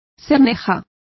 Complete with pronunciation of the translation of fetlocks.